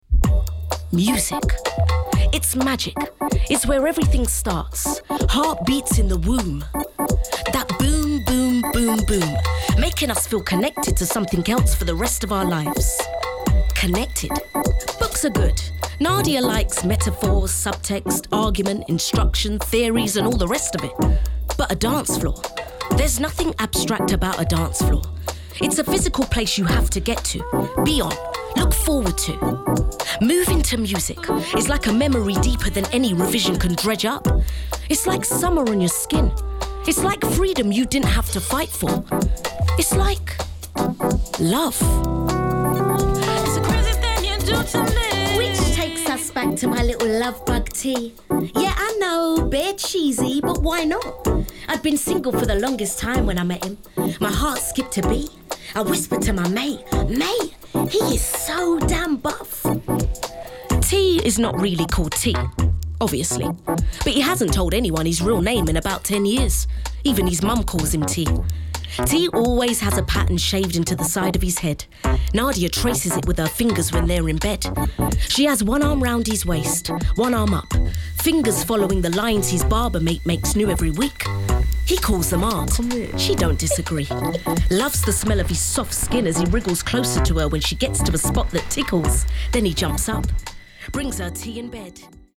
30/40's London, Likeable/Upbeat/Confident